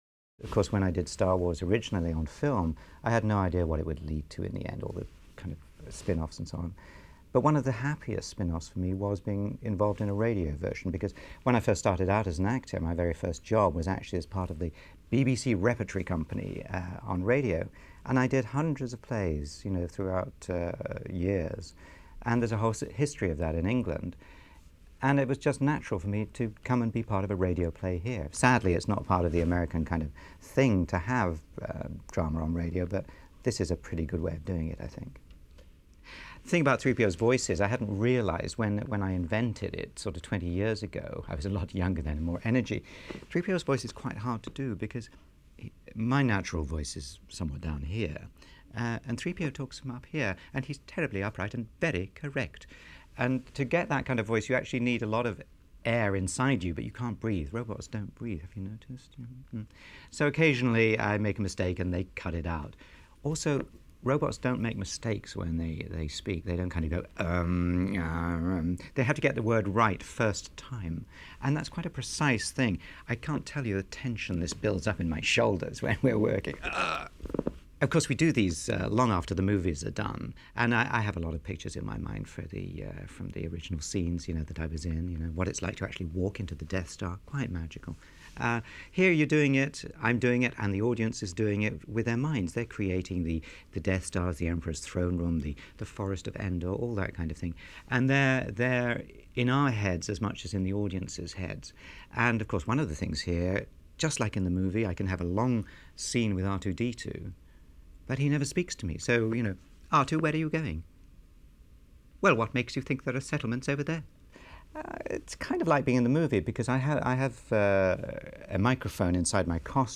07 - Bonus Track - Comments from Anthony Daniels During the Recording Sessions.flac